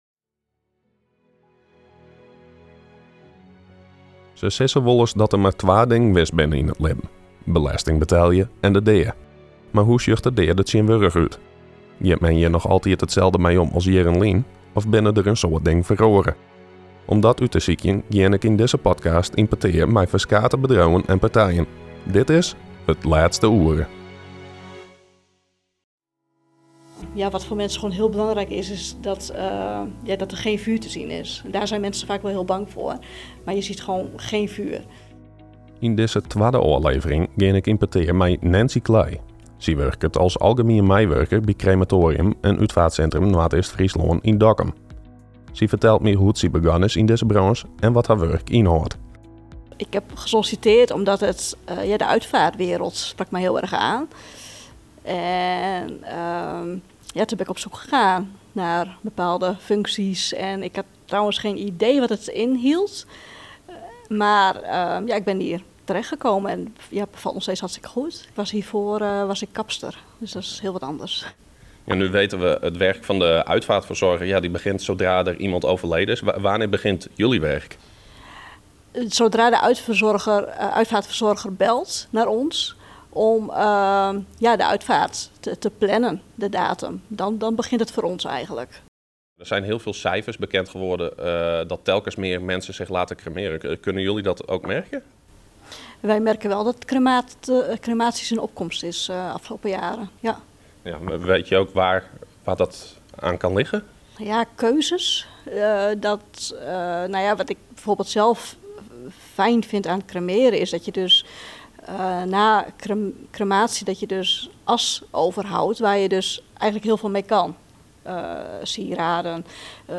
In deze podcast praten we met mensen die in dit vakgebied werken om te ontdekken hoe het werken rondom de dood tegenwoordig gaat.